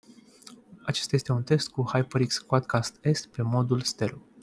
Sunet clar pentru toate modurile alese
• Stereo